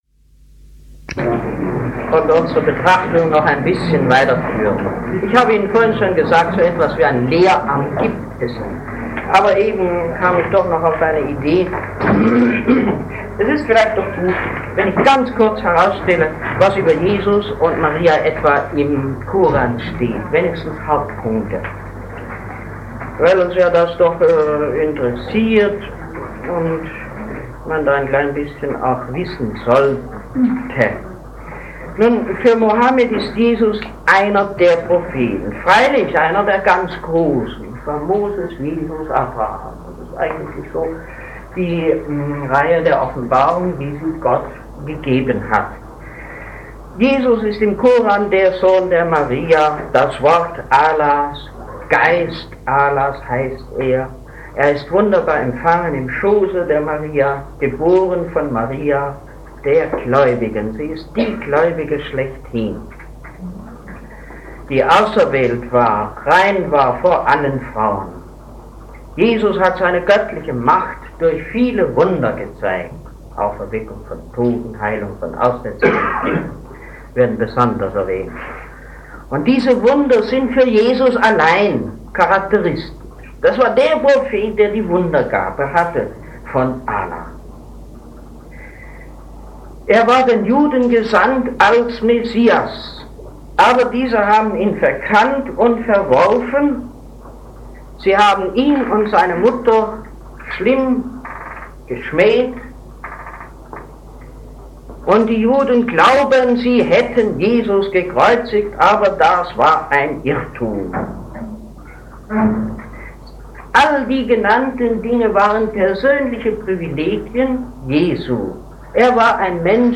Die religiösen Grundzüge des Islam II - Rede des Monats - Religion und Theologie - Religion und Theologie - Kategorien - Videoportal Universität Freiburg